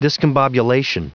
Prononciation du mot discombobulation en anglais (fichier audio)
Prononciation du mot : discombobulation